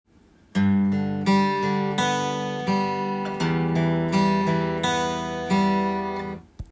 アルペジオ例
例の①～④すべて　ルート音からアルペジオが始まっています。